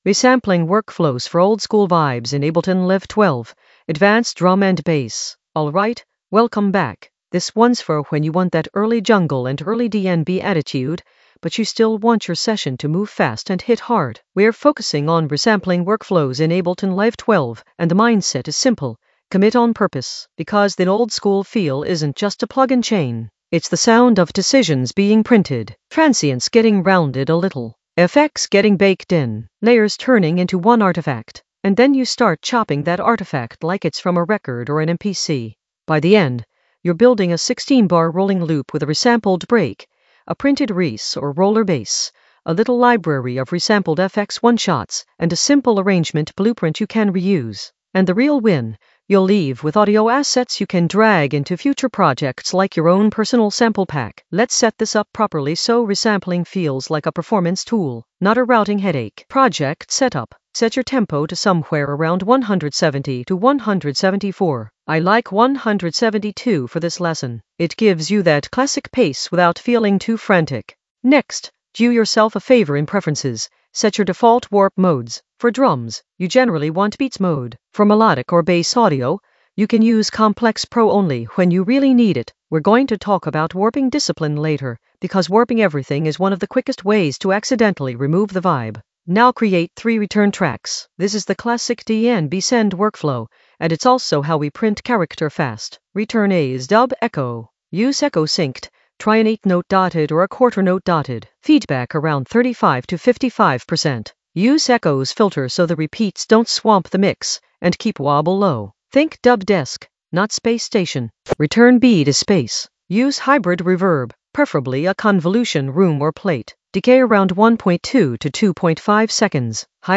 Narrated lesson audio
The voice track includes the tutorial plus extra teacher commentary.
An AI-generated advanced Ableton lesson focused on Resampling workflows for oldskool vibes in Ableton Live 12 in the Workflow area of drum and bass production.